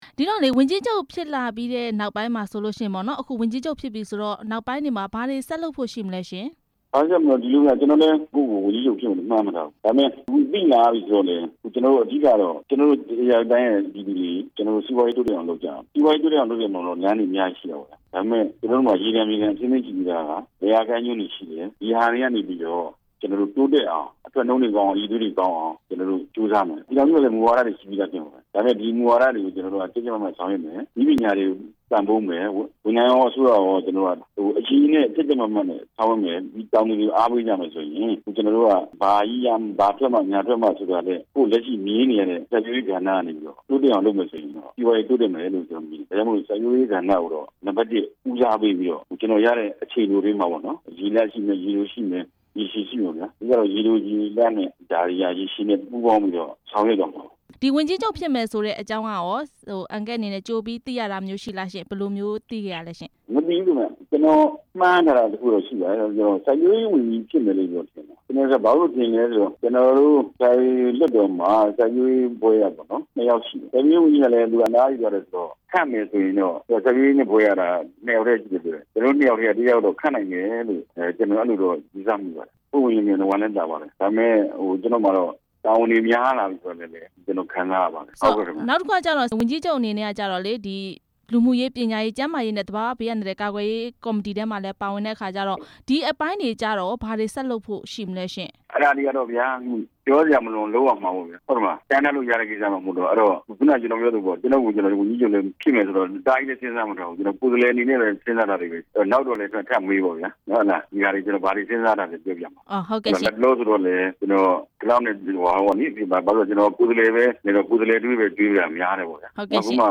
ဧရာဝတီတိုင်းဒေသကြီး ဝန်ကြီးချုပ် ဦးလှမိုးအောင်နဲ့ မေးမြန်းချက်